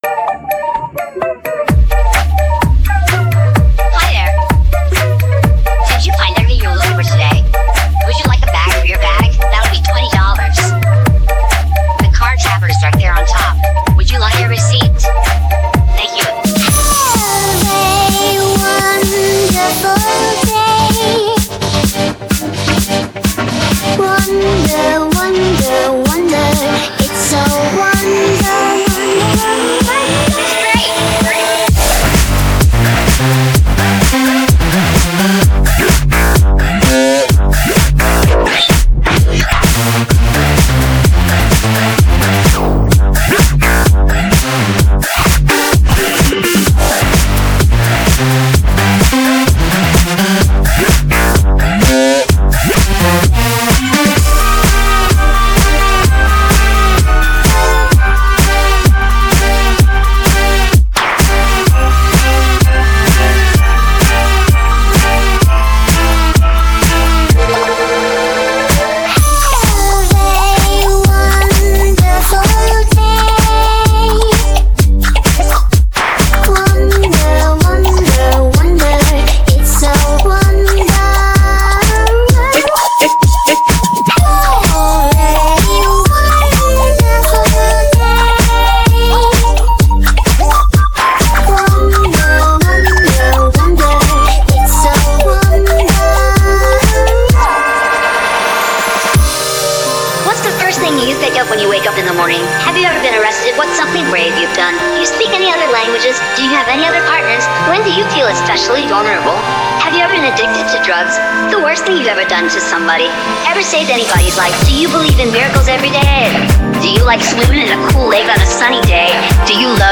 Here are a few electronic music tracks I recently created.
Using Audacity for editing, mixing, and mastering, I mashed up content generated by Udio from my original lyrics and custom prompts, with organically recorded music and sounds, to create these tracks.